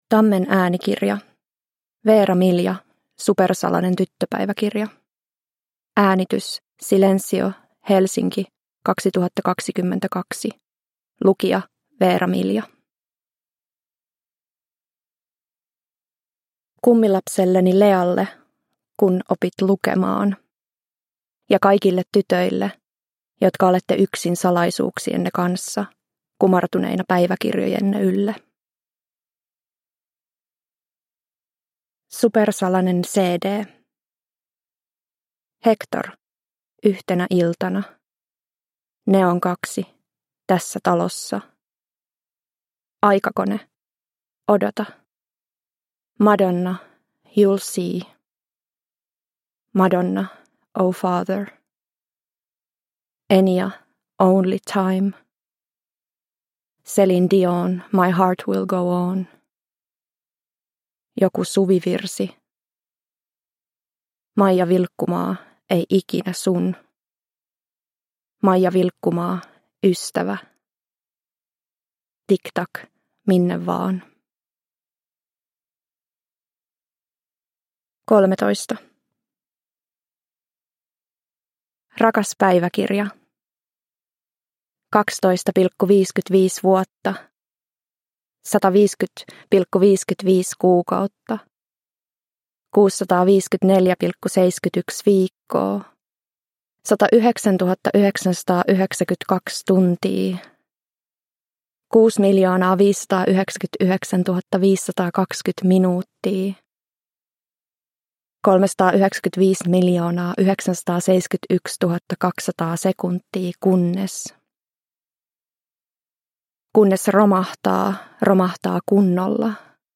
Supersalanen tyttöpäiväkirja – Ljudbok – Laddas ner